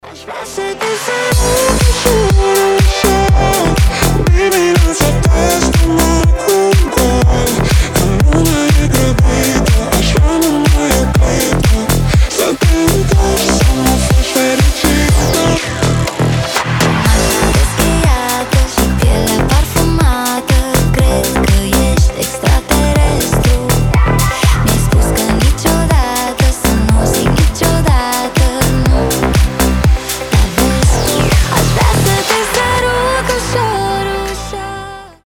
• Качество: 320, Stereo
deep house
женский голос
ремиксы